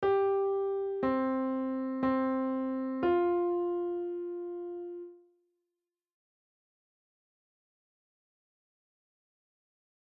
On the piano, play The Wheels On The Bus
G C C F